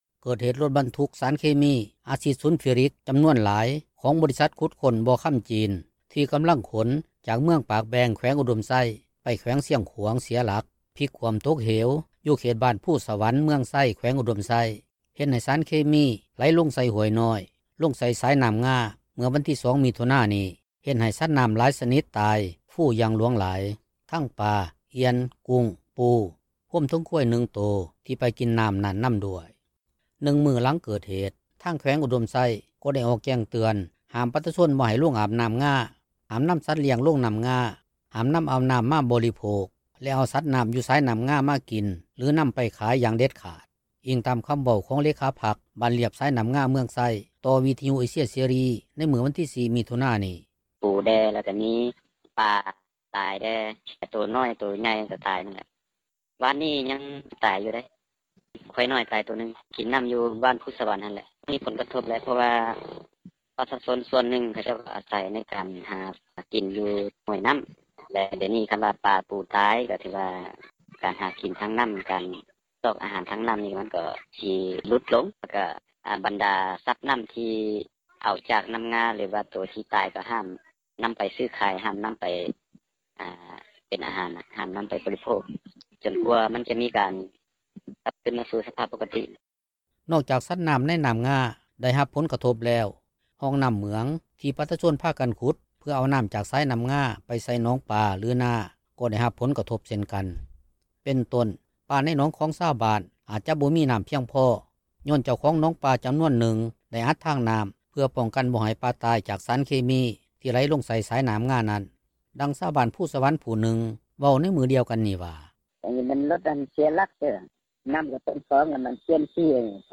ດັ່ງຊາວບ້ານພູສະຫວັນ ຜູ້ນຶ່ງເວົ້າໃນມື້ດຽວກັນນີ້ວ່າ:
ດັ່ງຊາວບ້ານພູສະຫວັນ ຜູ້ດຽວກັນນີ້ ເວົ້າຕື່ມວ່າ: